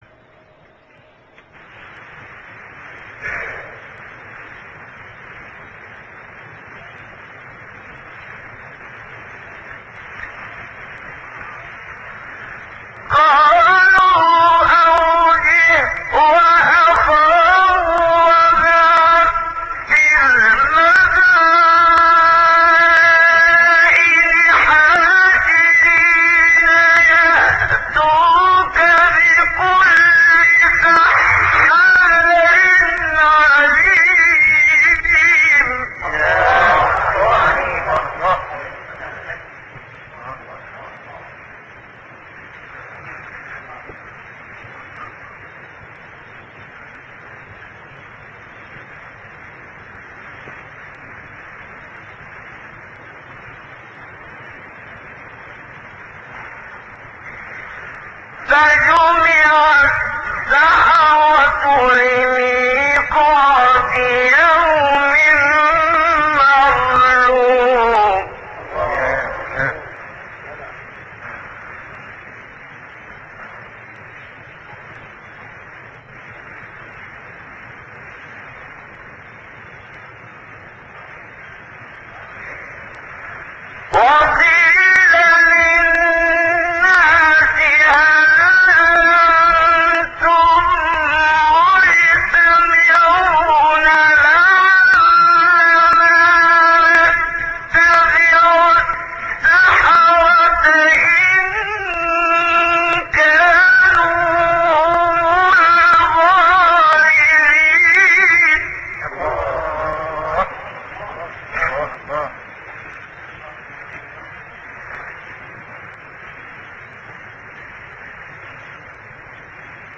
سایت-قرآن-کلام-نورانی-منشاوی-رست-2.mp3